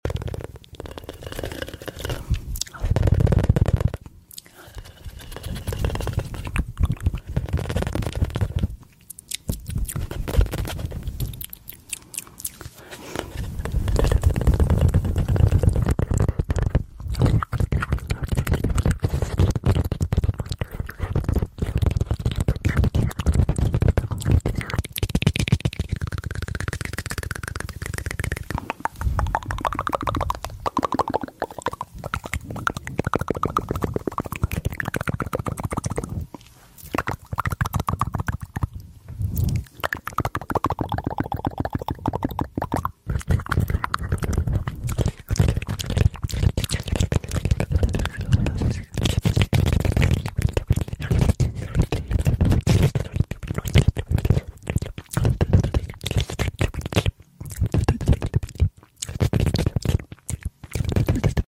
Asmr | Mouth Sonds